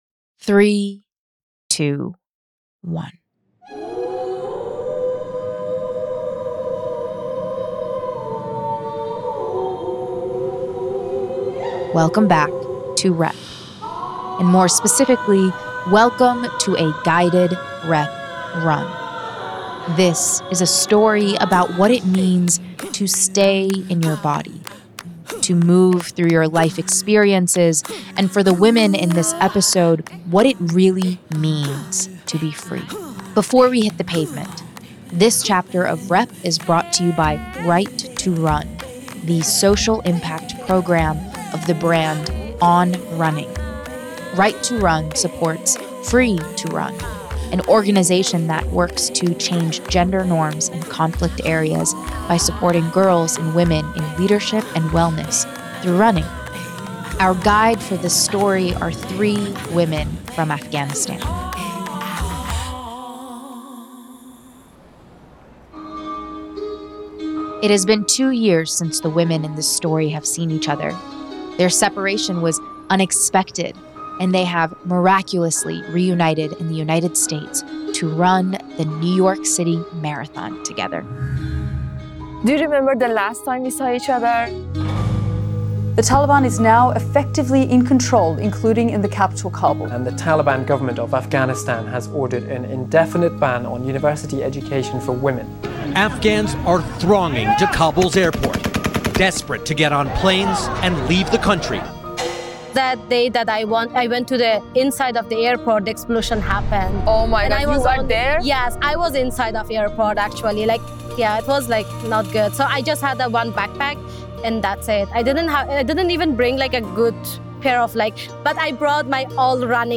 And more specifically – welcome to a guided REP Run. This is a story about what it means to stay in your body, to move through your life experiences, and for the women in this episode, what it really means to be Free.
12. Free to Run (ft. 3 Afghan Women Runners)